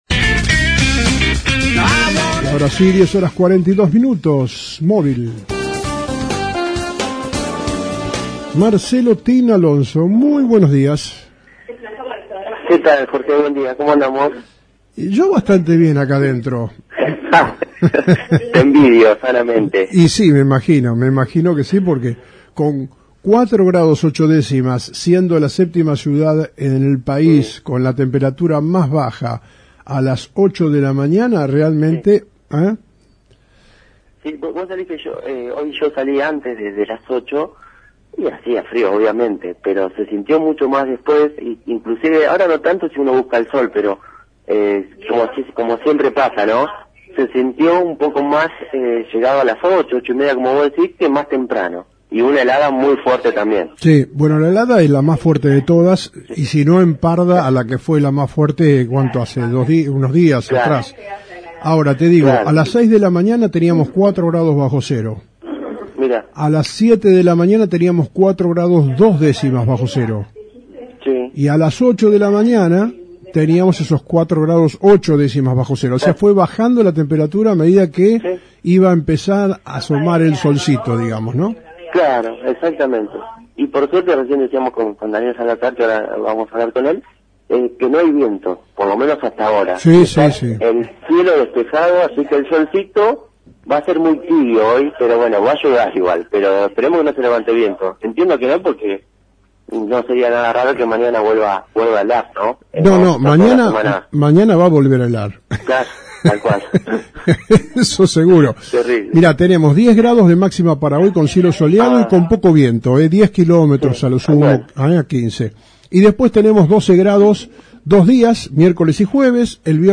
Disertación en la UCR sobre la Situación Hídrica y Caminos Rurales :: Radio Federal Bolívar